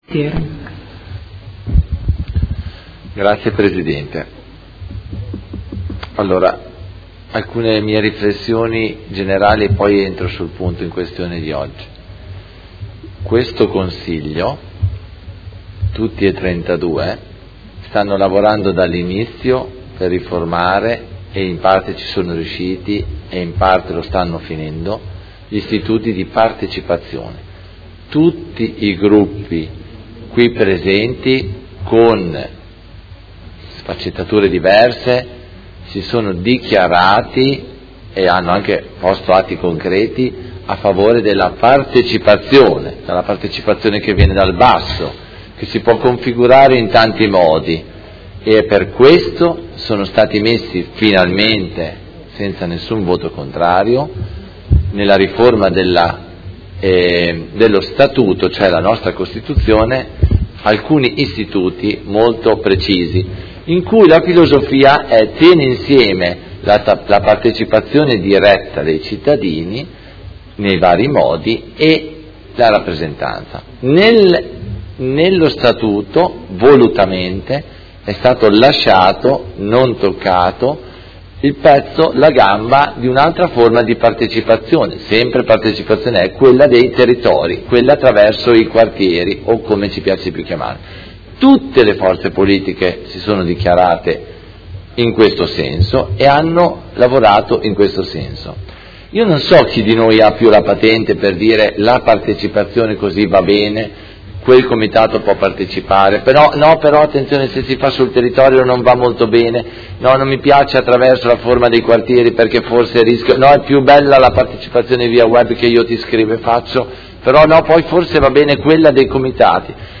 Seduta del 3/05/2018. Dibattito su proposta di deliberazione: Quartieri – Surrogazione di consiglieri dei Quartieri 1, 2, 3 e 4 e su Mozione presentata dal Gruppo Consiliare PD avente per oggetto: A sostegno dei quartieri e della loro azione presente e futura